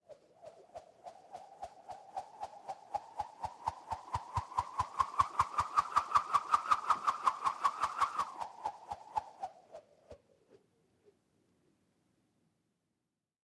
Звуки ударов кнутом
Звук раскручиваемого кнута и лассо